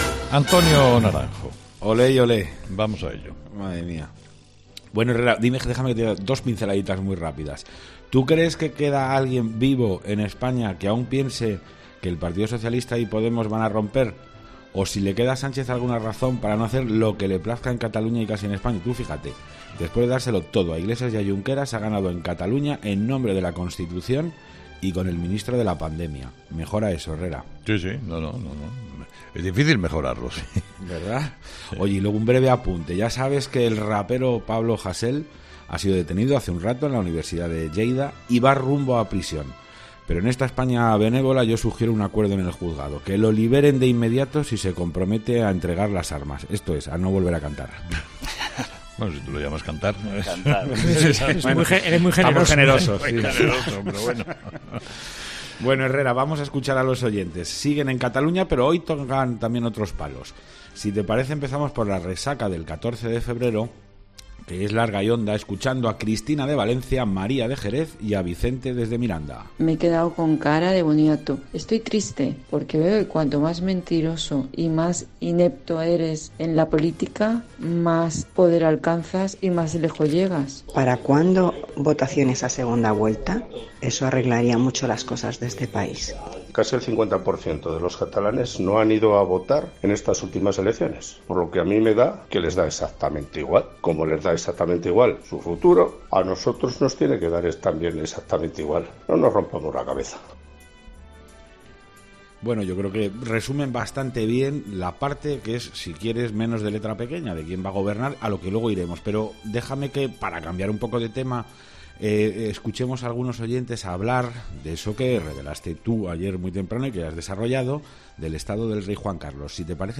La tertulia de los oyentes de Carlos Herrera